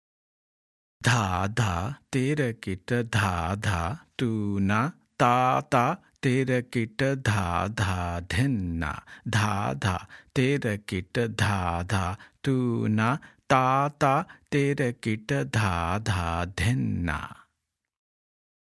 1x Speed – Spoken